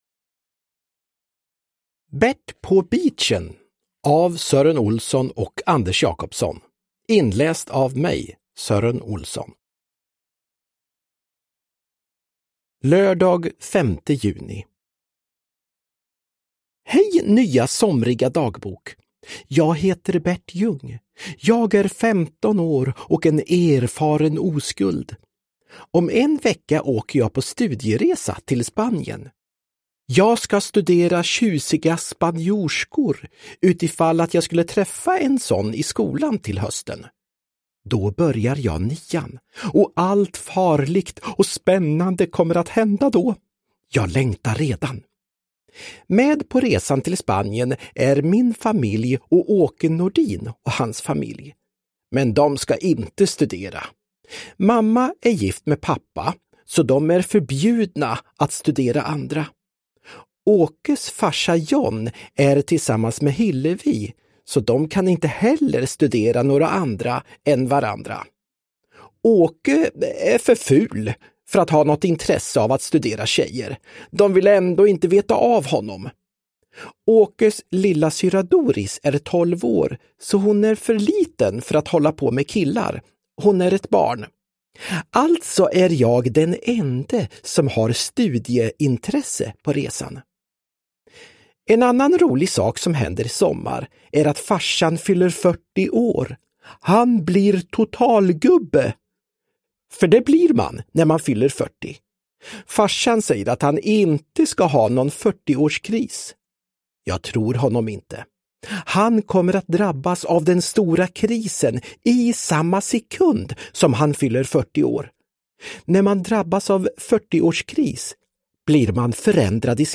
Bert på beachen – Ljudbok – Laddas ner
Uppläsare: Sören Olsson